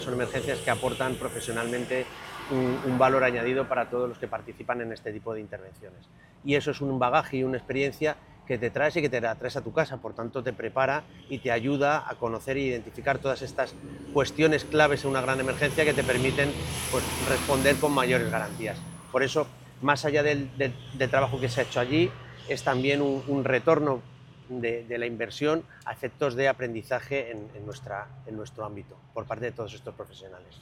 Escolta aquí el director general d’Emergències en rebre el contingent al Port de Palma:
853-pablo-garriz.mp3